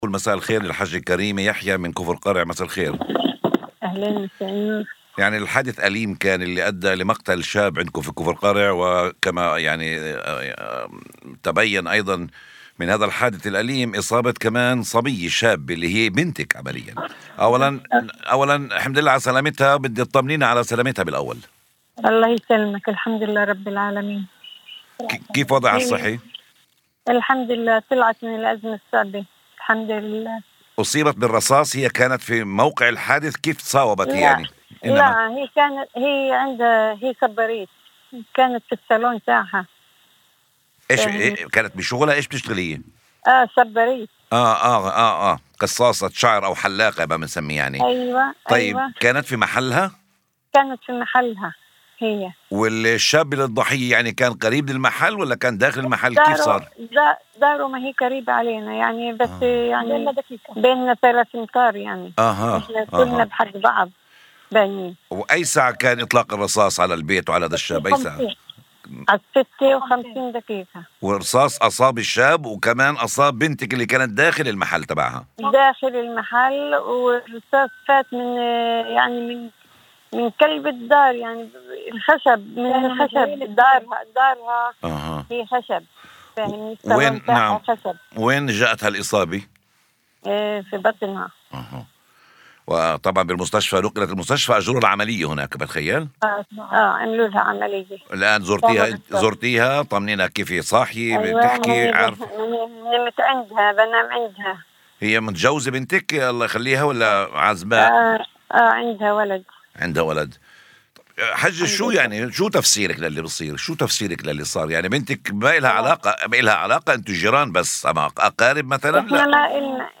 في مداخلة لإذاعة الشمس عبر برنامج "الحصاد الإخباري"